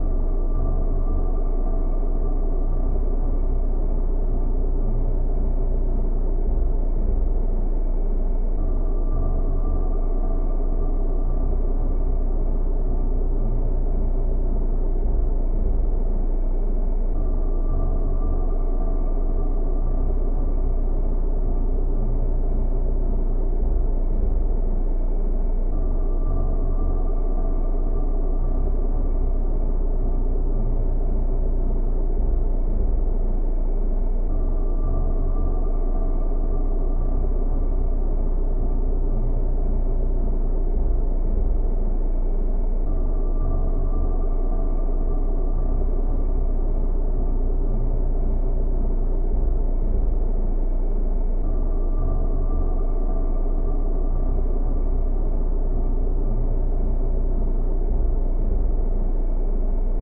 Type BGM
Speed 80%